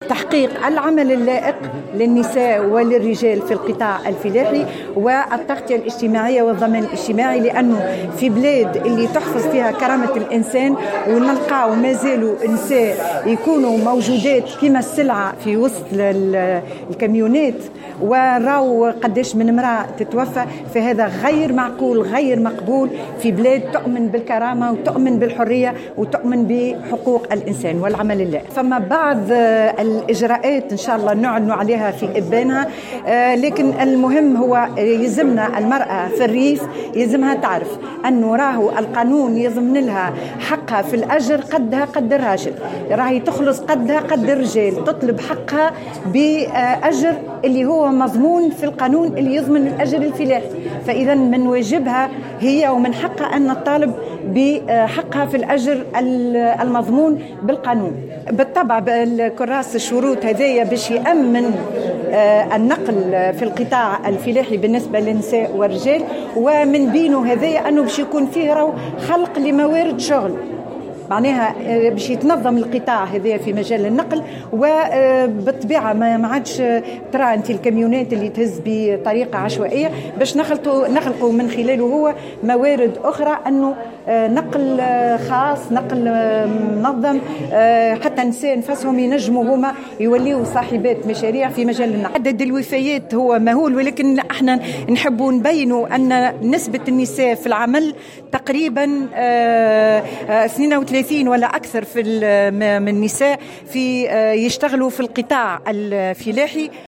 أكدت وزيرة المرأة، نزيهة العبيدي اليوم خلال ندوة صحفية خصصت للإعلان عن برنامج الاحتفال باليوم العالمي للمرأة الريفية أنه سيتم الإعلان عن جملة من الإجراءات لفائدة المرأة العاملة في القطاع الفلاحي.
وزيرة المرأة